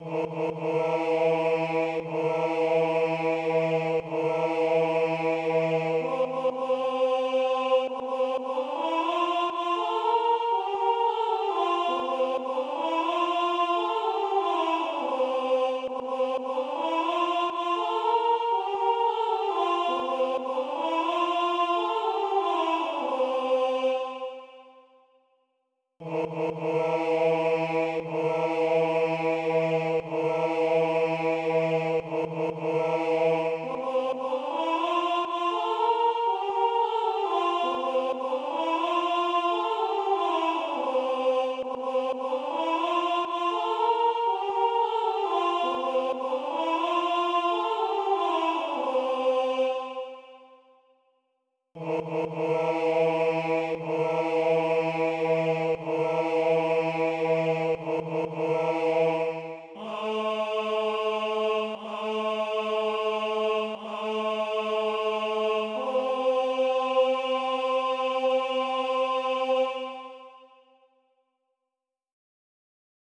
Ténor - HAH voix Tp